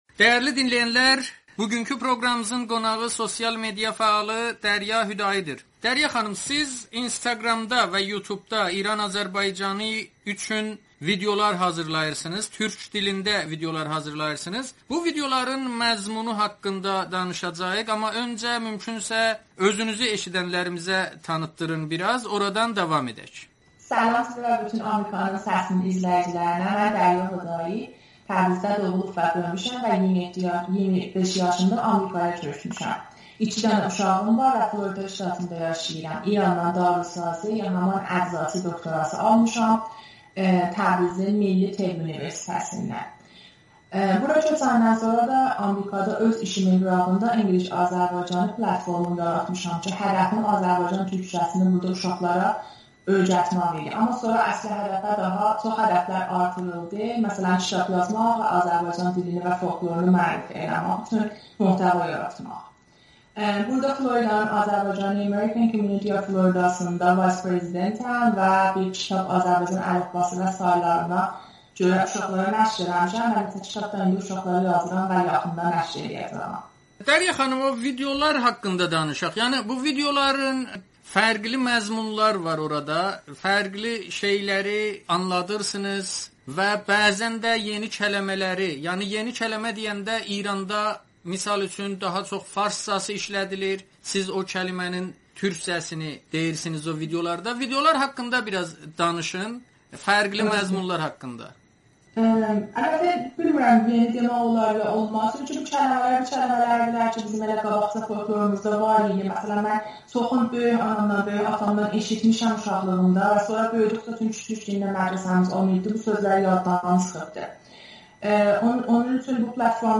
Sosial media fəalı Amerikanın Səsinə müsahibədə İran Azərbaycanı və mühacirətdə yaşayan uşaqlara türk dilini öyrətmək, habelə dil və folklor üzrə məzmunlar yaratmaq məqsədilə bu fəaliyyətə başladığını ifadə edir.